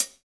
Index of /90_sSampleCDs/Roland L-CDX-01/KIT_Drum Kits 4/KIT_Hard Core
HAT HATSO09L.wav